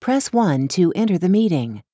cospace_join_confirmation.wav